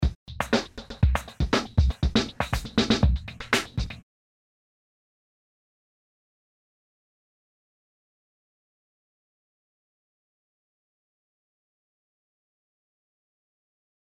図3：図2の状態からノート位置を変更した状態。